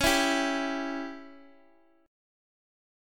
Dbdim chord
Db-Diminished-Db-x,x,x,6,5,3-1-down-Guitar-Standard-1.m4a